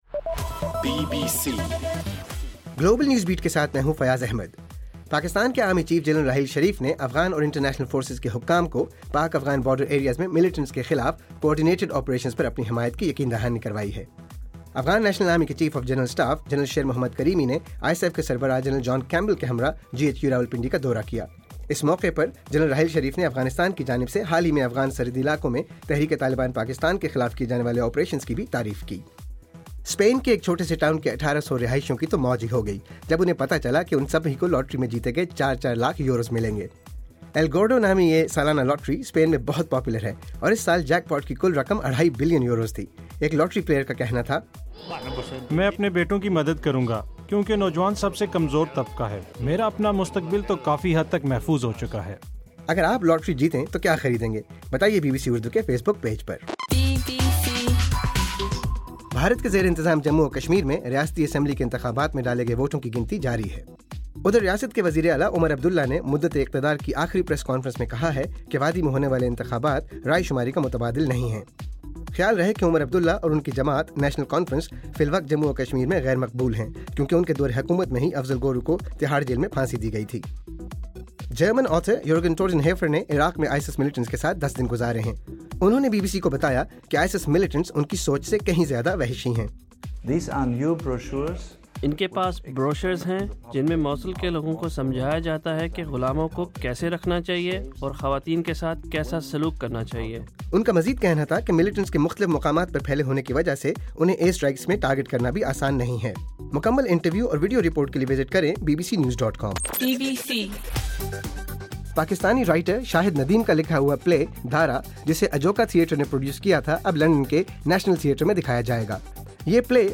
دسمبر 23: رات 11 بجے کا گلوبل نیوز بیٹ بُلیٹن